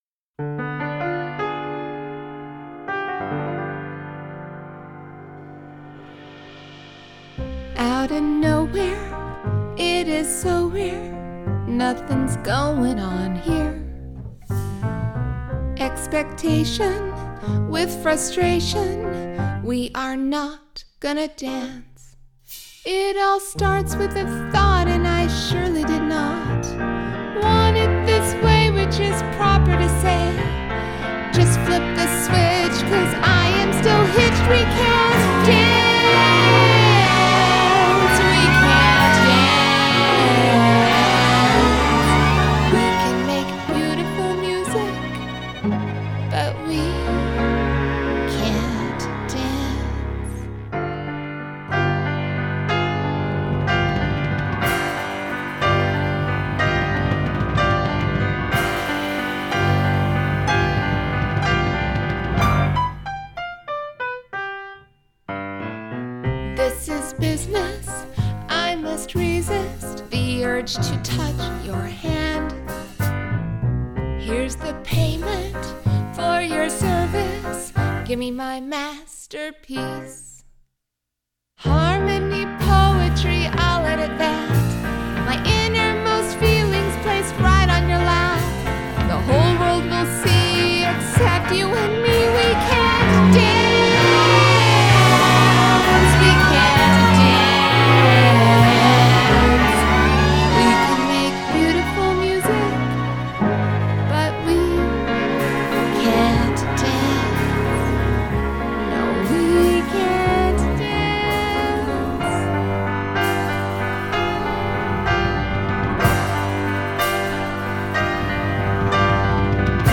Adult Contemporary , Comedy
Indie Pop , Musical Theatre